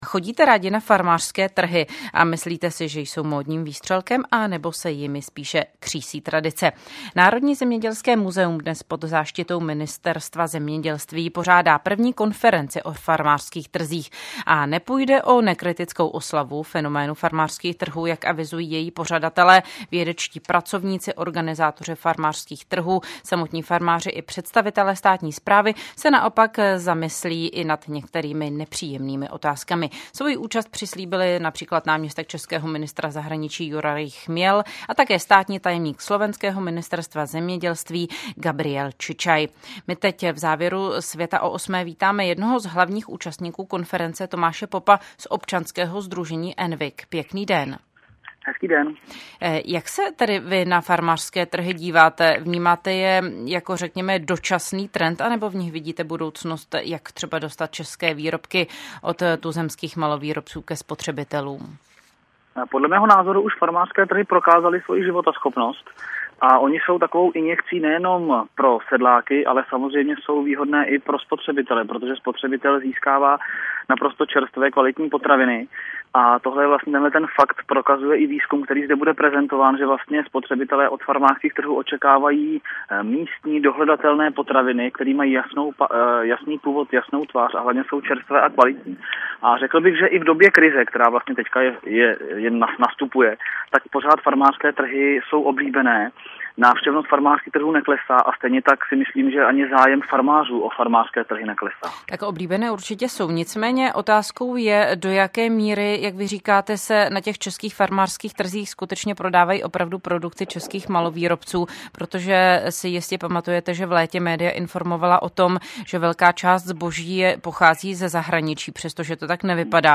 Záznam vysílání je přiložen pod textem.